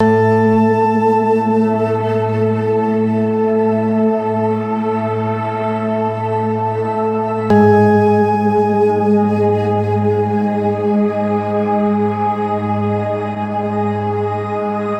Tag: 128 bpm Electronic Loops Strings Loops 2.52 MB wav Key : A